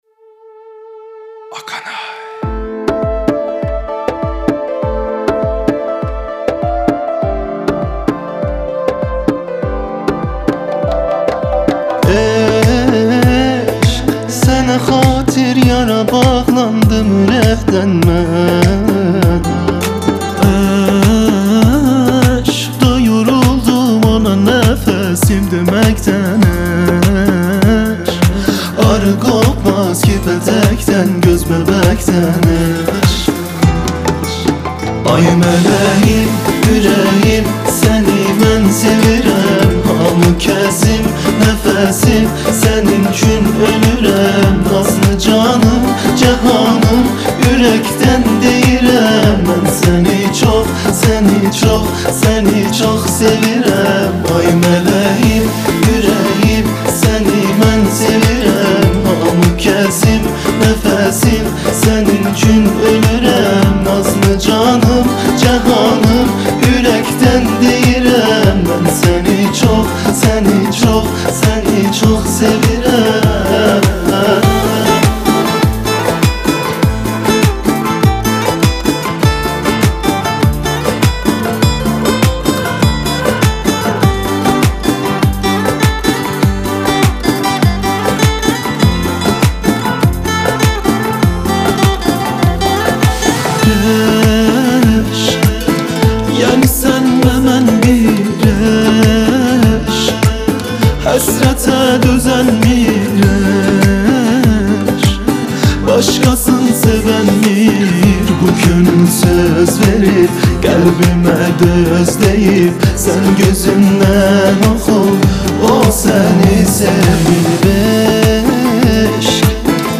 آهنگ ترکی من سنی چوخ سویرم